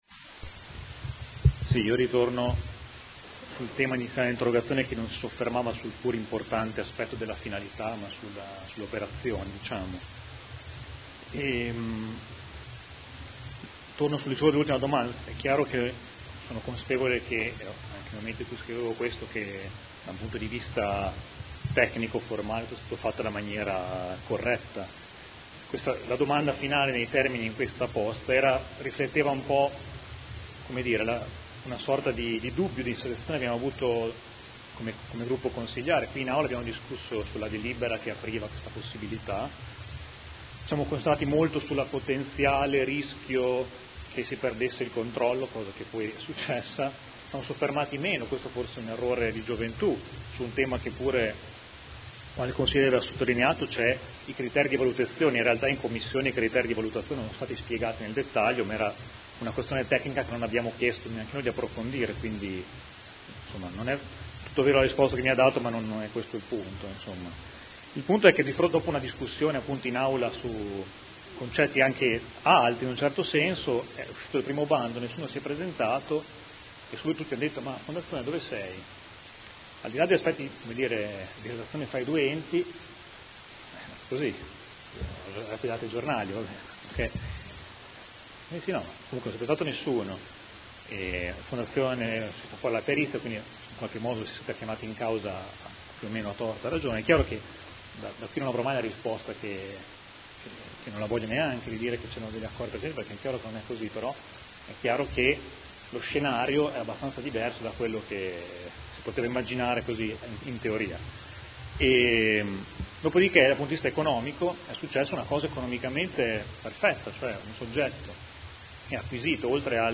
Seduta del 22 ottobre. Interrogazione del Gruppo Consiliare Movimento 5 Stelle avente per oggetto: Informazioni a corredo del bando di vendita azioni Farmacie Comunali.